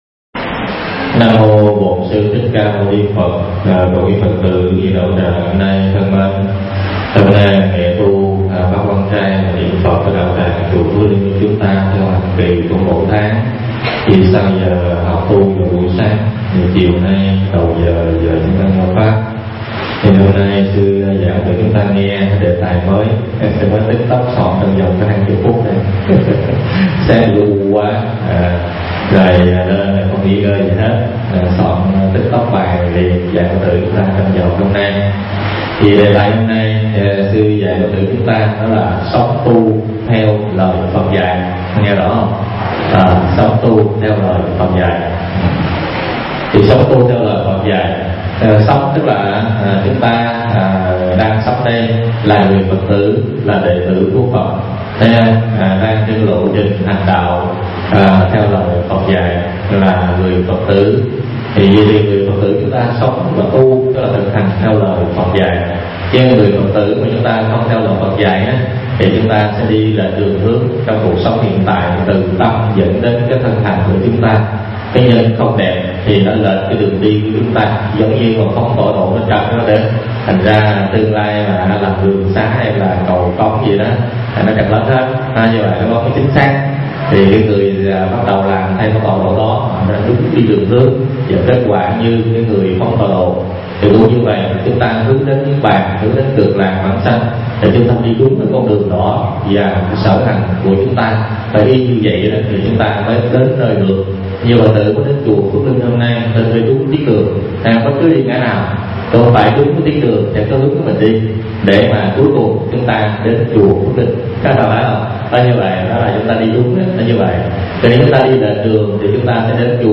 Tải mp3 Pháp Âm Sống Tu Theo Lời Phật Dạy